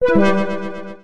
メール音やSMSの通知音。